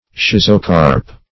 Schizocarp \Schiz"o*carp\, n. [Schizo- + Gr. ? fruit.] (Bot.)